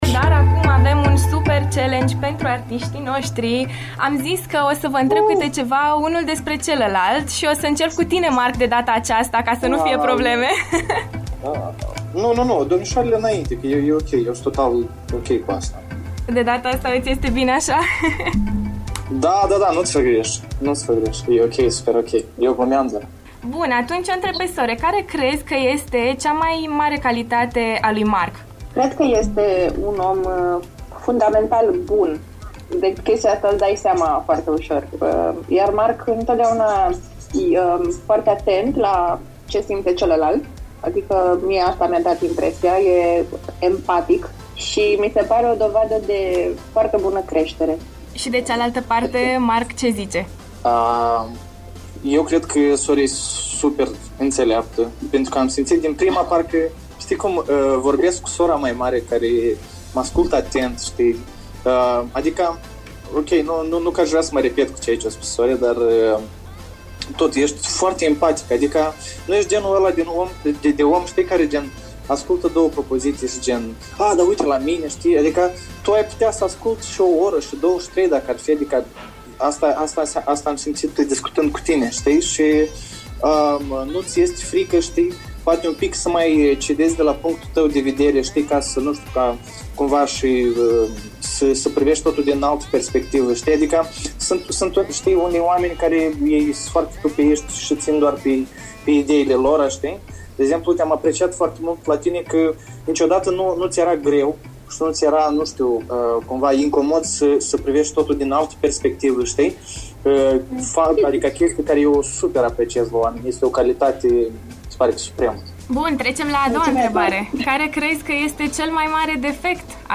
Home Emisiuni After Morning Cât de bine se cunosc Sore și Mark Stam? Challenge LIVE în After Morning